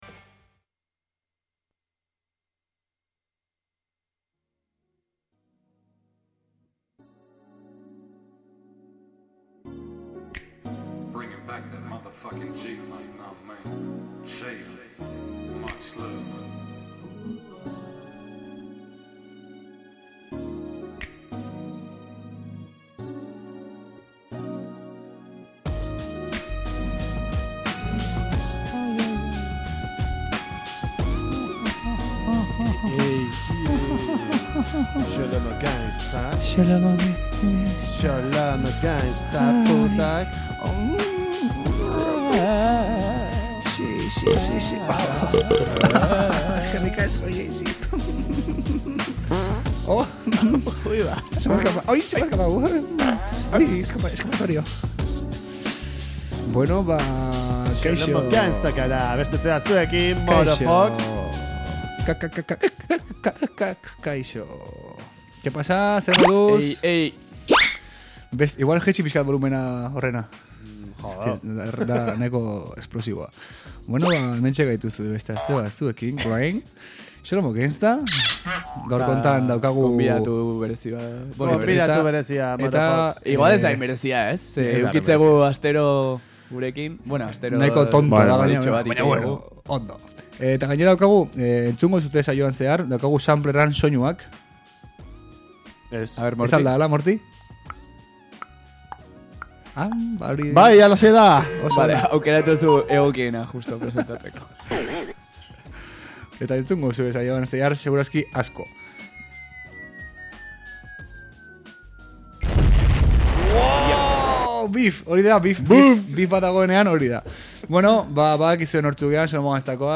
Euskal Herriko eta nazioarteko rap musika izan da entzugai Xolomo Gangsta saioan.